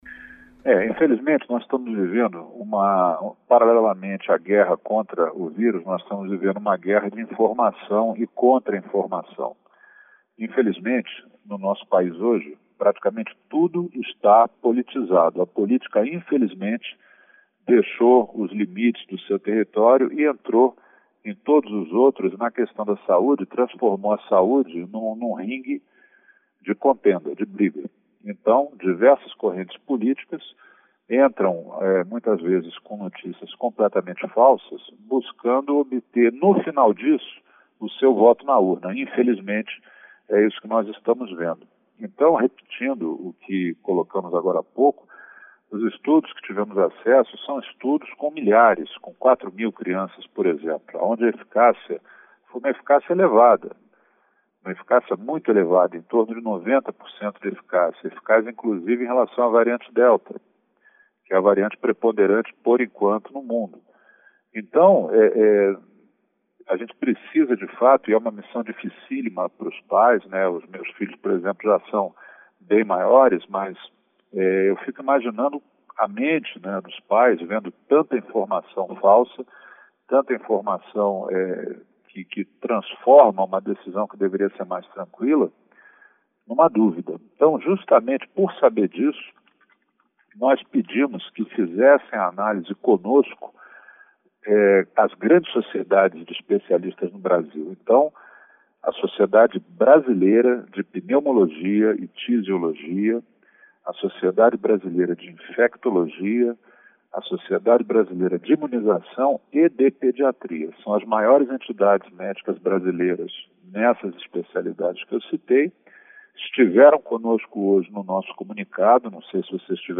Decisão de vacinar crianças é 100% técnica, diz diretor da Anvisa